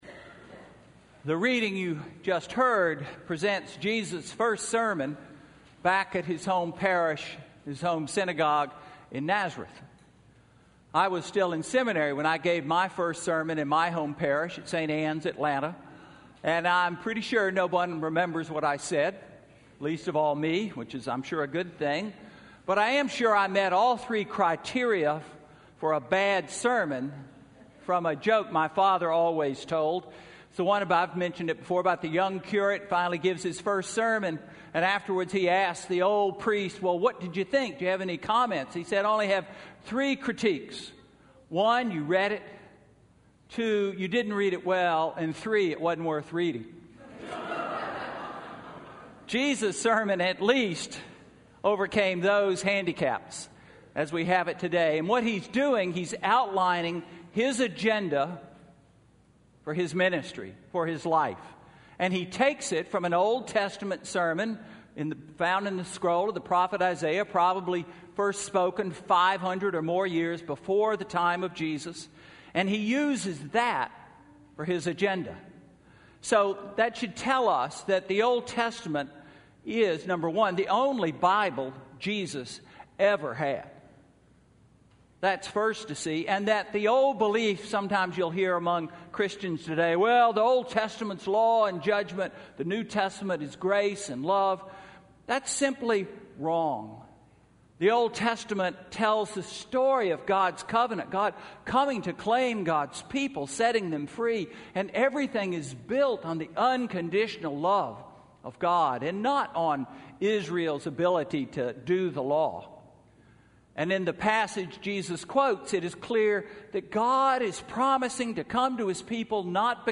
Sermon–January 24, 2016